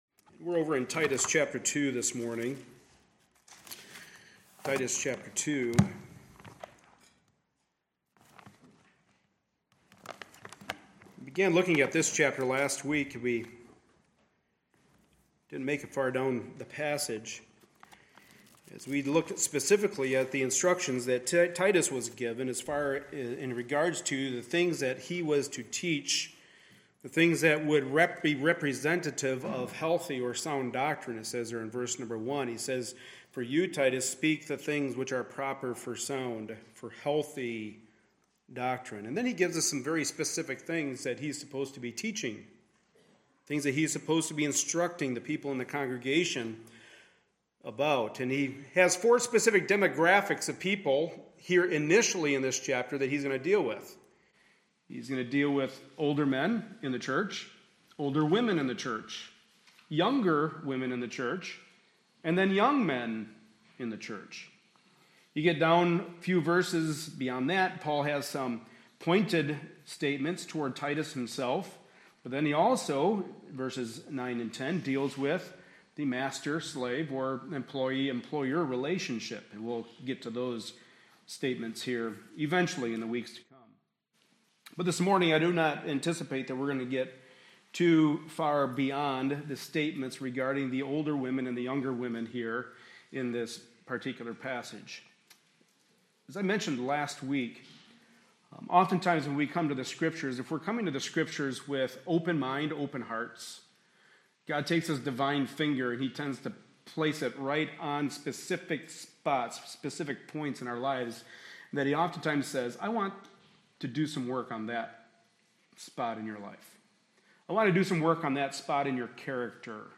Passage: Titus 2:1-15 Service Type: Sunday Morning Service